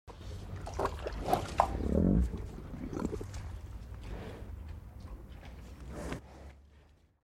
جلوه های صوتی
دانلود صدای غذا خوردن گاو از ساعد نیوز با لینک مستقیم و کیفیت بالا